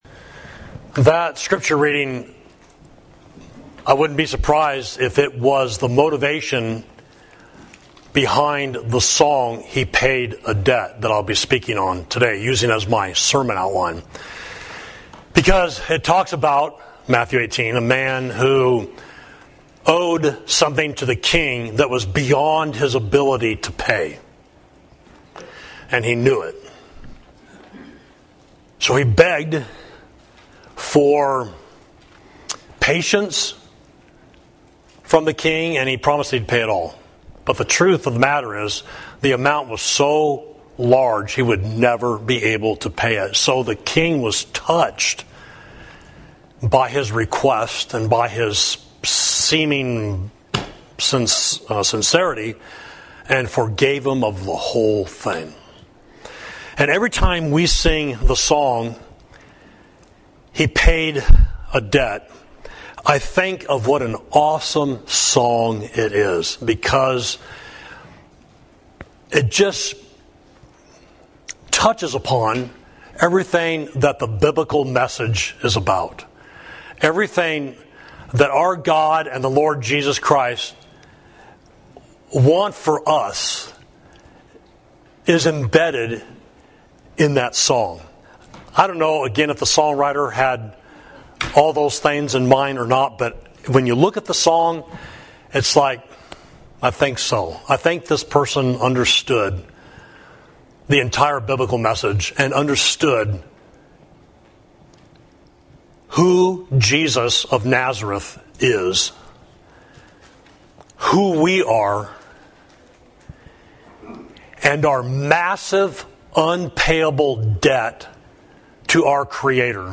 Sermon: He Paid a Debt He Did Not Owe